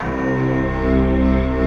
Index of /90_sSampleCDs/Optical Media International - Sonic Images Library/SI1_JapFluteOrch/SI1_SlowJapFlute